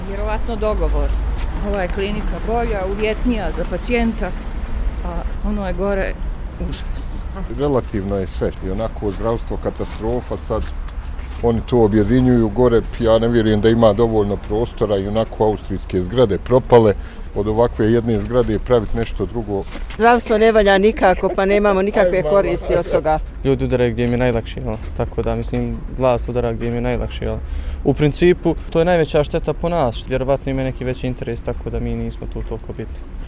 Anketirani građani Sarajeva ovako komentarišu cijeli slučaj: